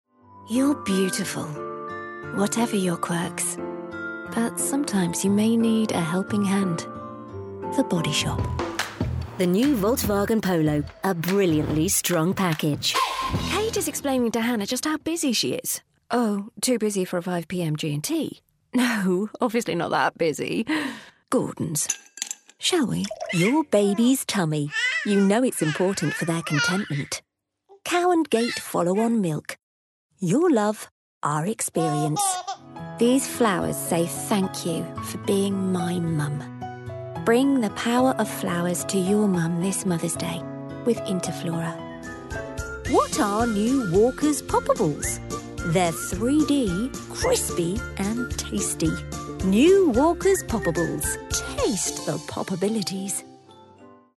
Demo
Young Adult, Adult
Accents:
british rp | natural